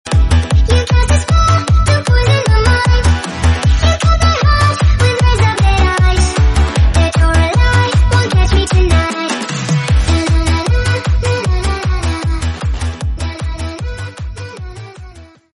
поп
ритмичные
быстрые
детский голос
цикличные